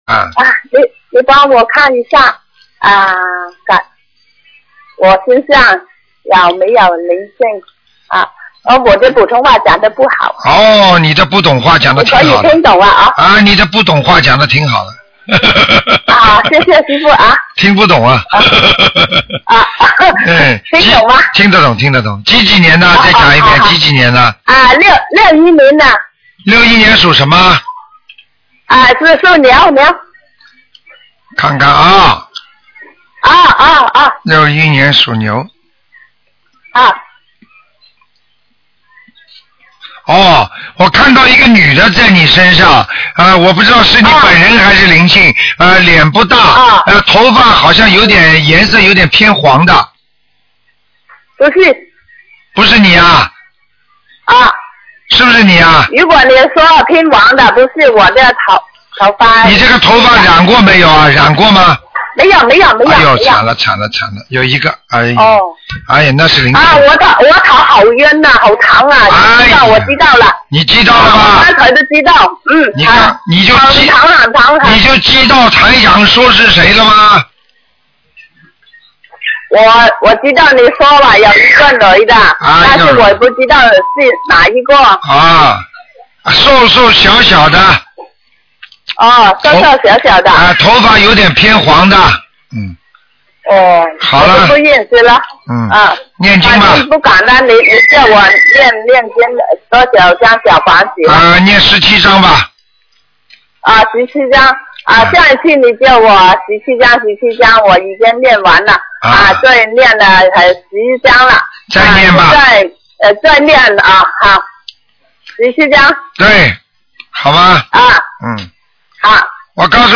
目录：剪辑电台节目录音_集锦